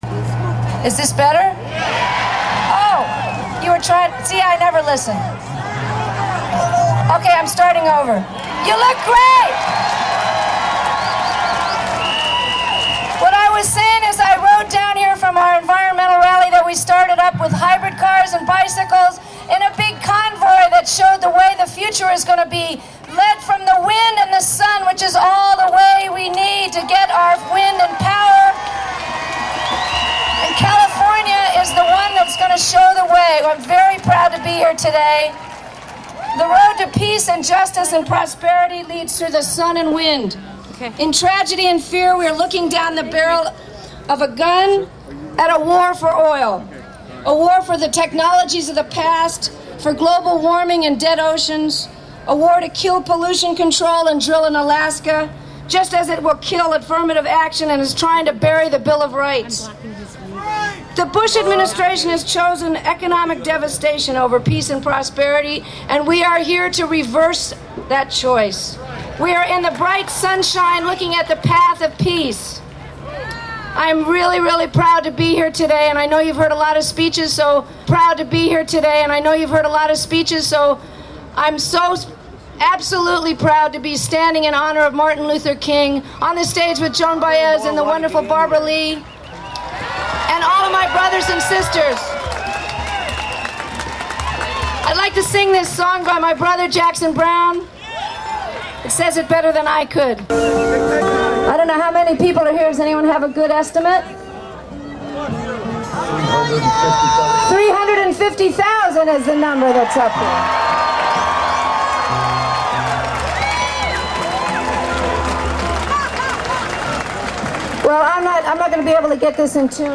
Bonnie Raitt's Speech - San Francisco
Audio - Bonnie Raitt Live In San Francisco (MP3 - Hi-res - 4 MB)